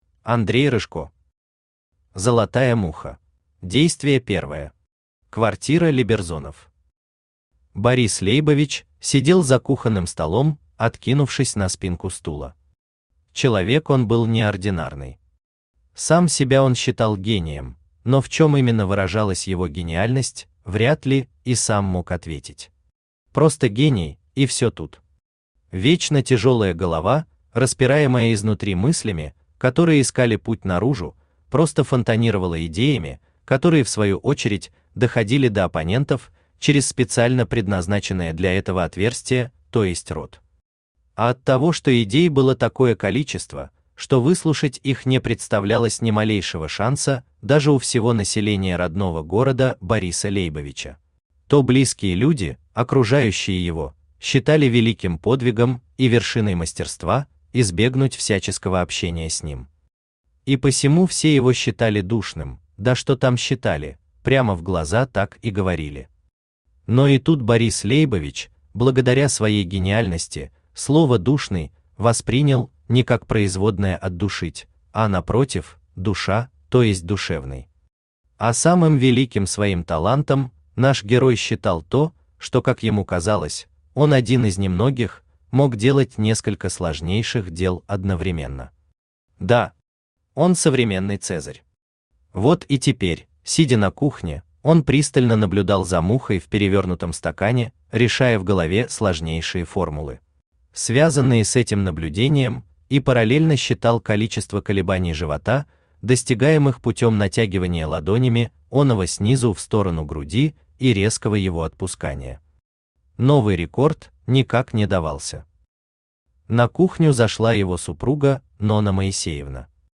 Аудиокнига Золотая муха | Библиотека аудиокниг
Aудиокнига Золотая муха Автор Андрей Николаевич Рыжко Читает аудиокнигу Авточтец ЛитРес.